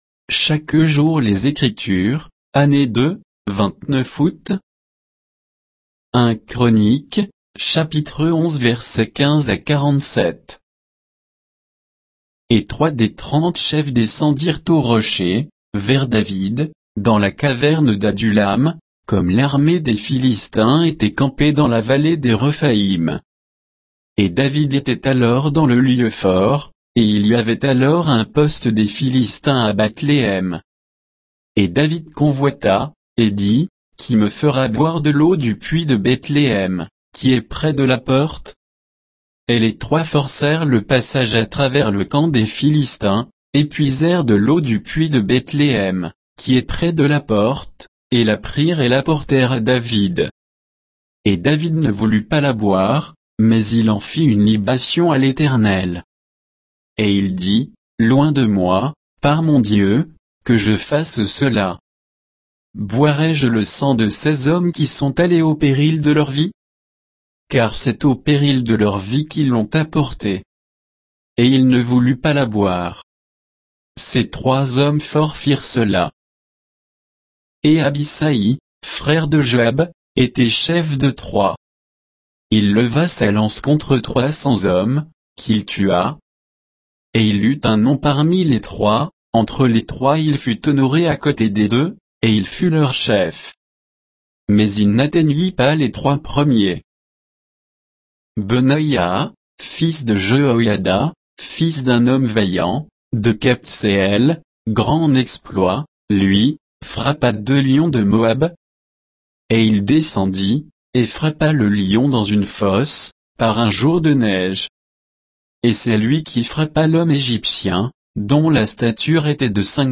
Méditation quoditienne de Chaque jour les Écritures sur 1 Chroniques 11, 15 à 47